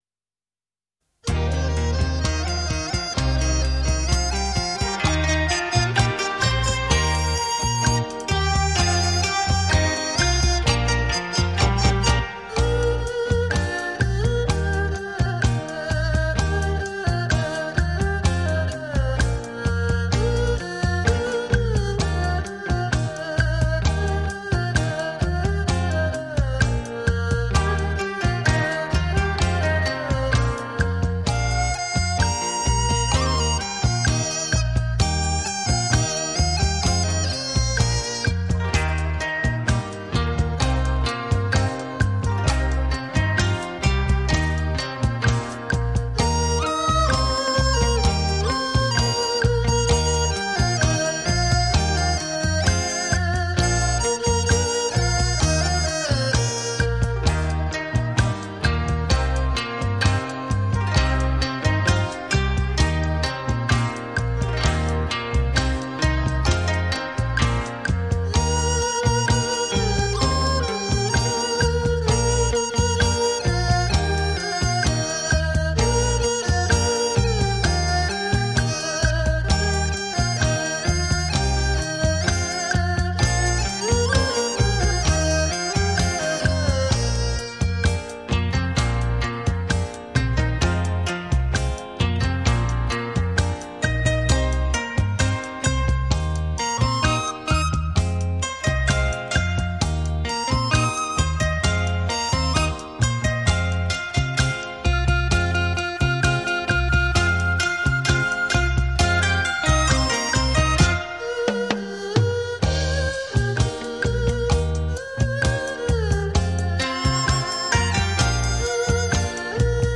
古筝（二胡）